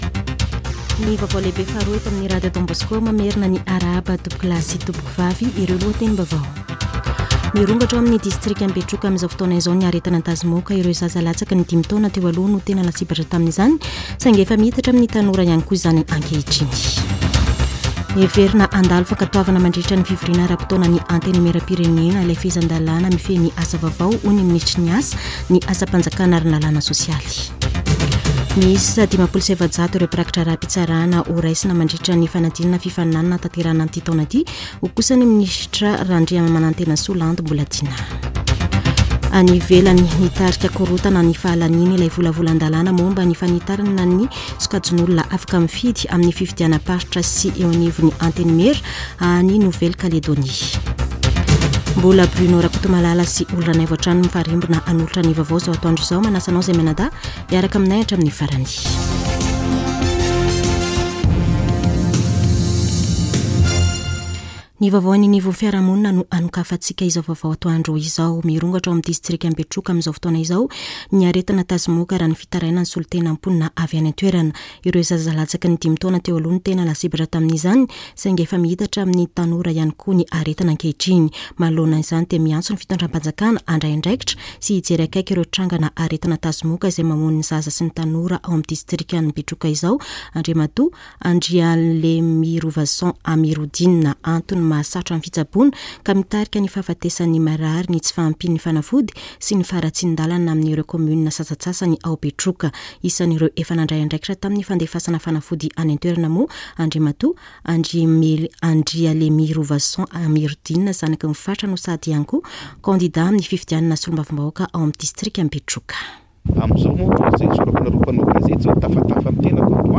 [Vaovao antoandro] Alakamisy 16 mey 2024